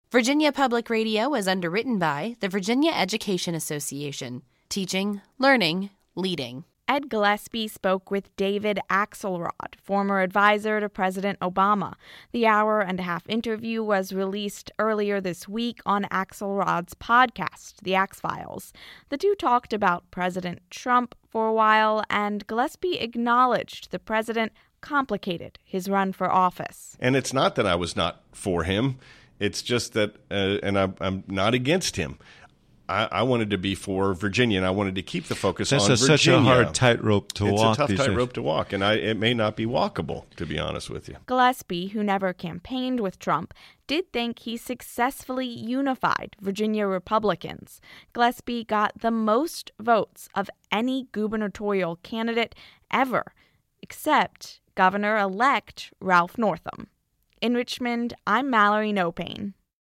vpr-gillespie-post-election.mp3